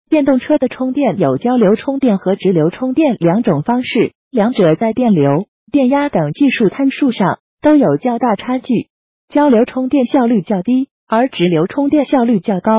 充电桩.mp3